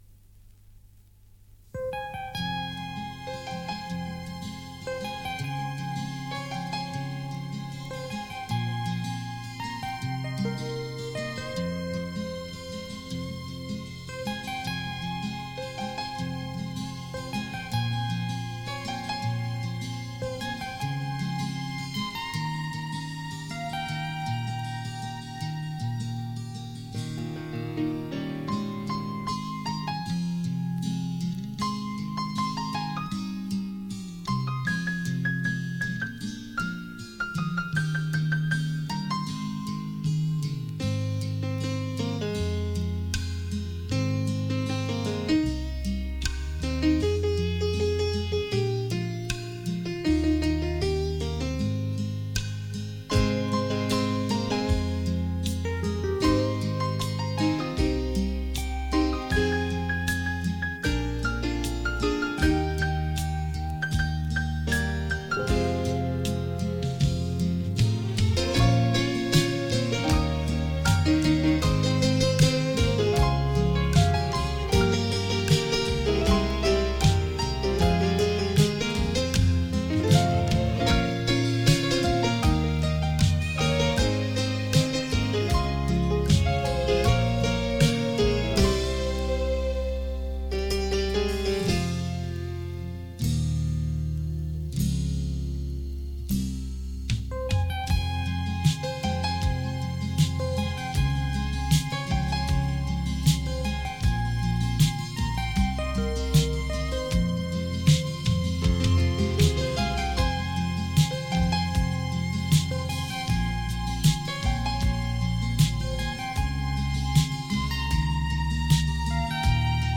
令人回味的音韵 仿佛回到过往的悠悠岁月